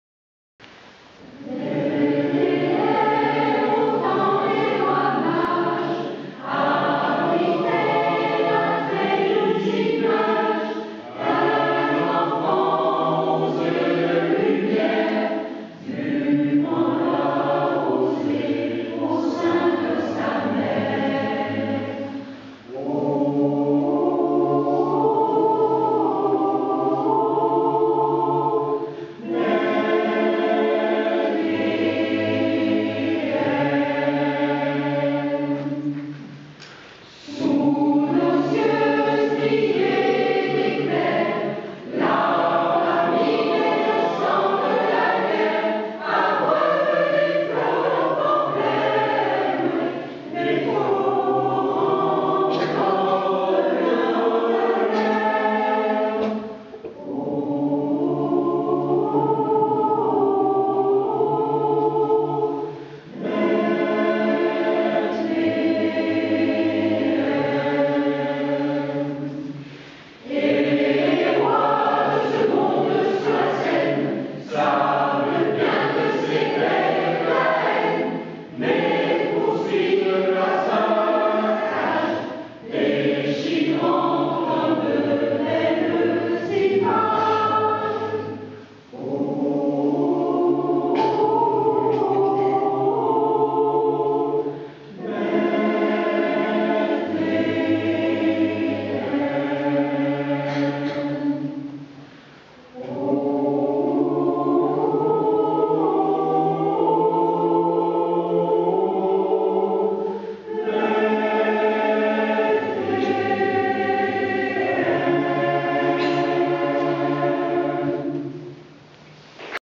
- Œuvre pour chœur à 3 voix mixtes (SAH) a capella